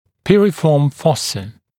[ˈpɪrɪfɔːm ‘fɔsə][ˈпирифо:м ‘фосэ]грушевидное отверстие